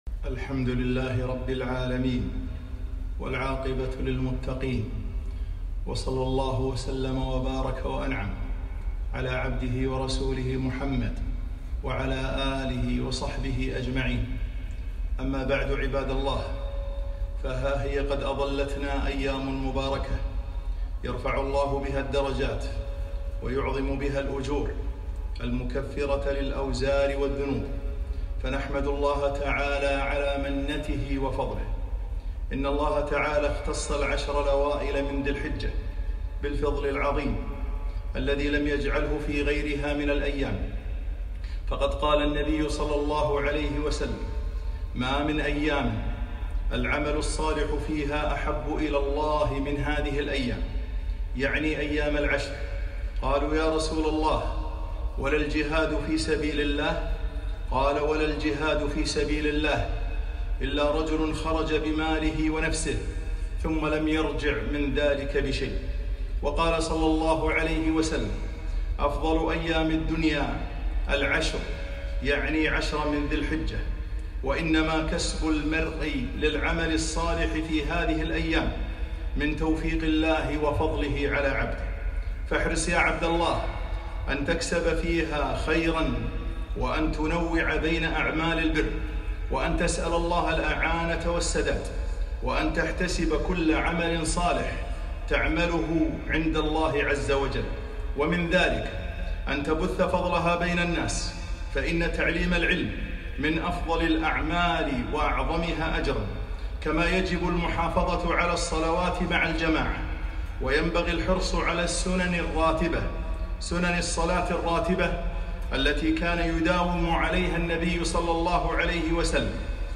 خطبة - حصاد الاجور في عشر ذي الحجة